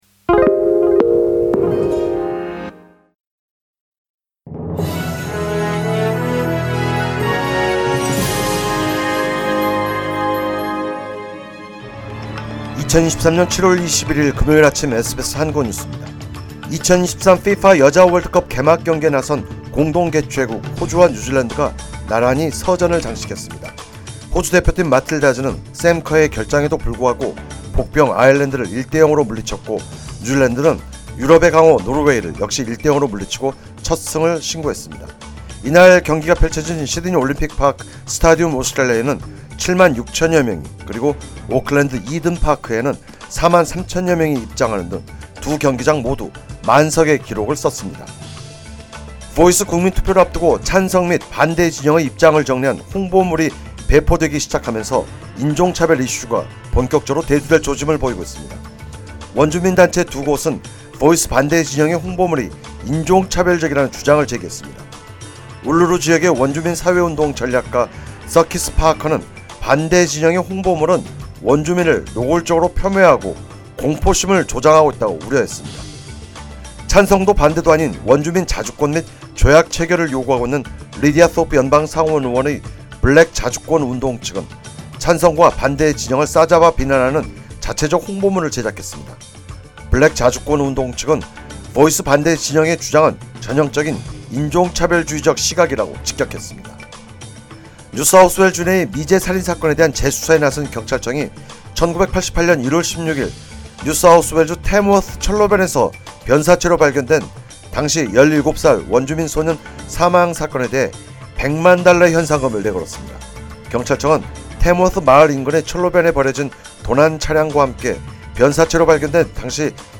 2023년 7월 21일 금요일 아침 SBS 한국어 뉴스입니다.